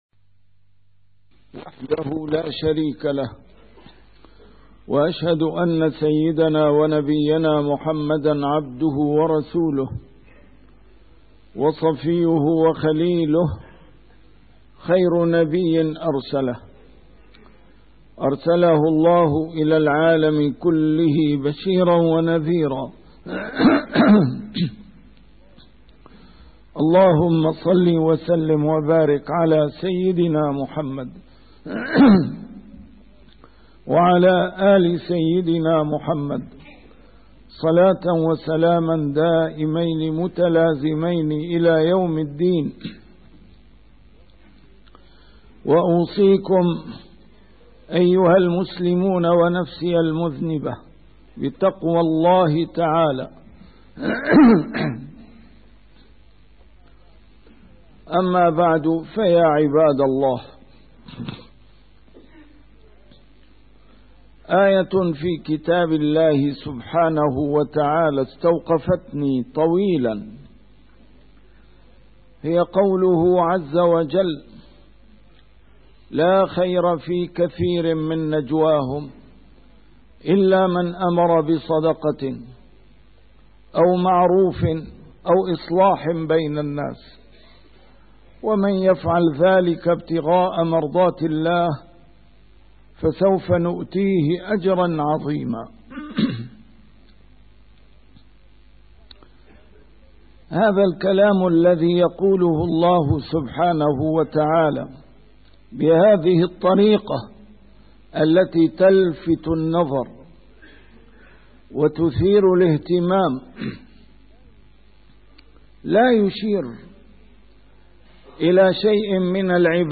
A MARTYR SCHOLAR: IMAM MUHAMMAD SAEED RAMADAN AL-BOUTI - الخطب - من ثمرات الإسلام الاجتماعية